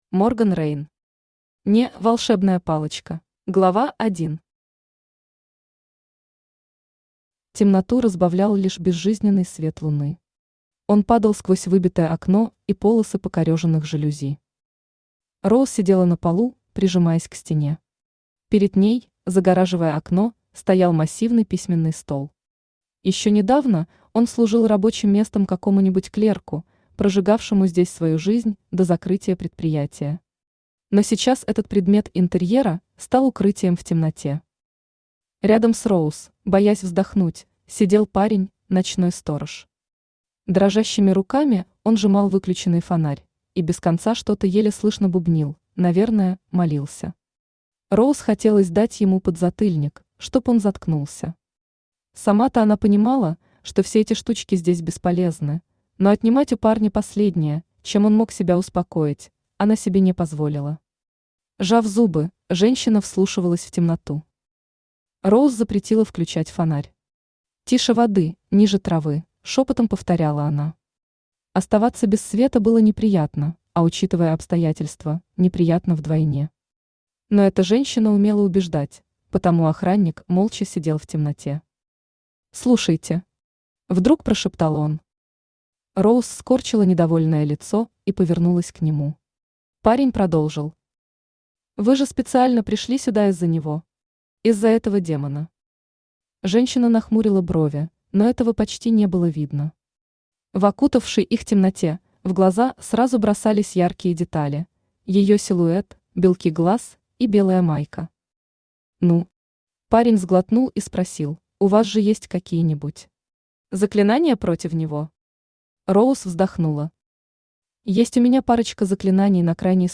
Аудиокнига (Не) волшебная палочка | Библиотека аудиокниг
Aудиокнига (Не) волшебная палочка Автор Морган Рейн Читает аудиокнигу Авточтец ЛитРес.